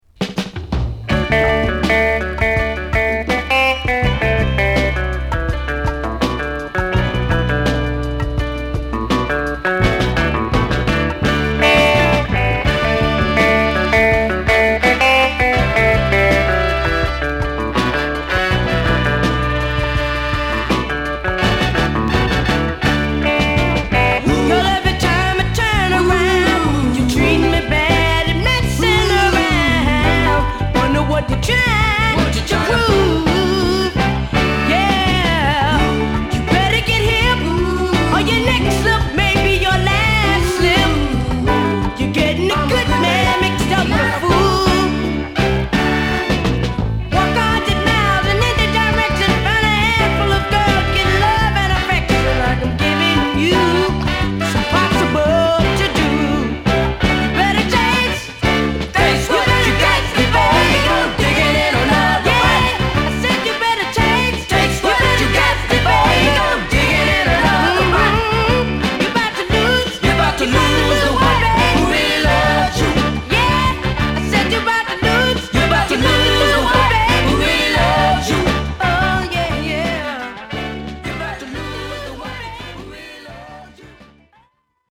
(Stereo)